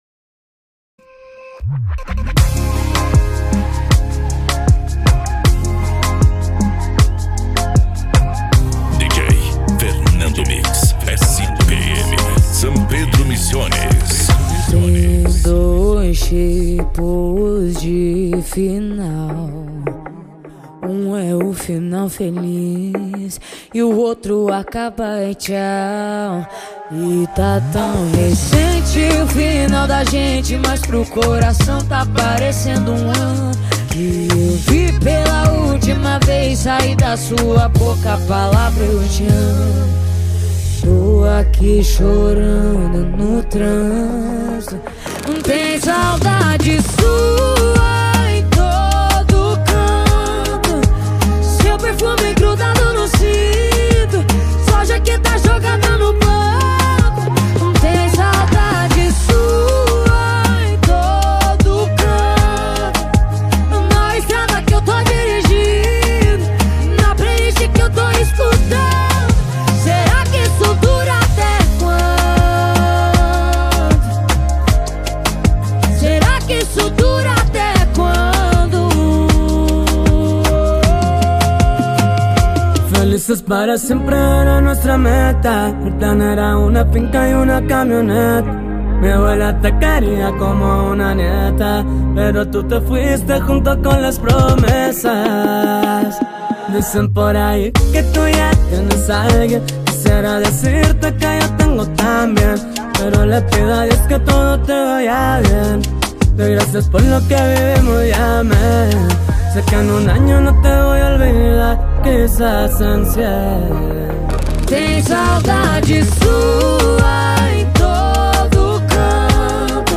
Original Com Grave Bass